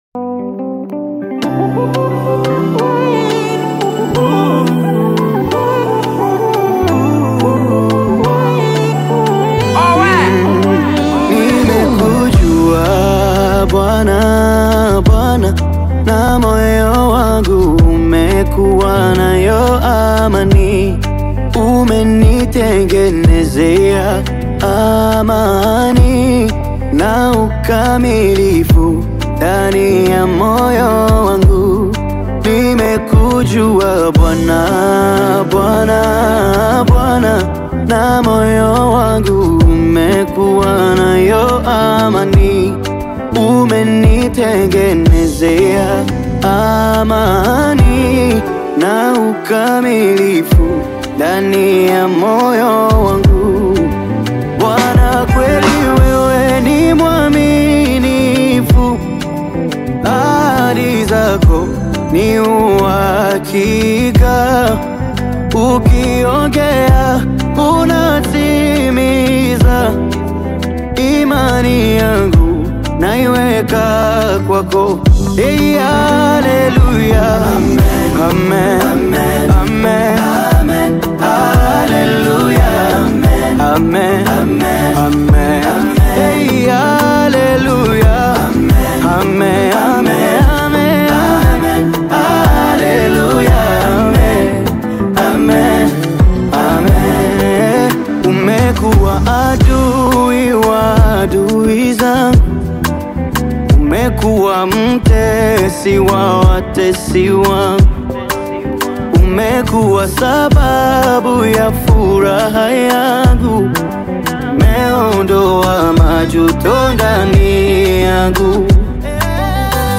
uplifting Tanzanian gospel single